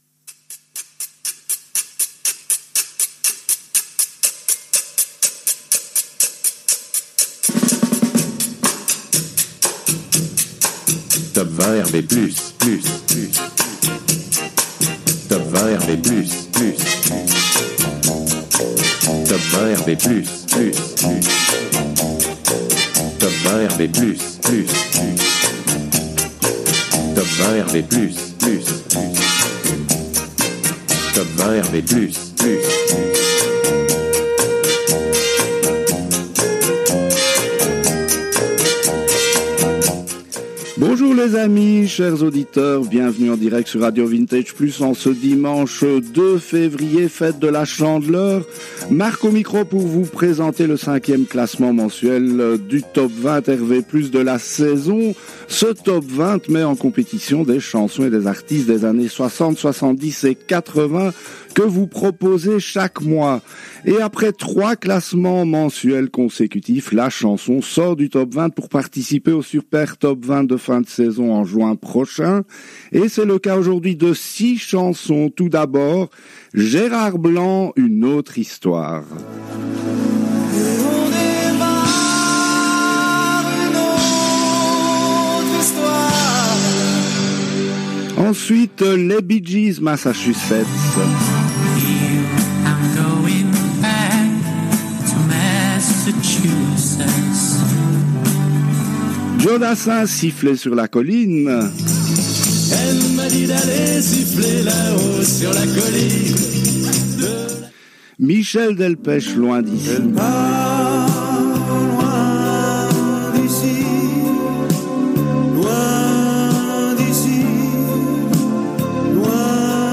en direct des studios belges de RADIO RV+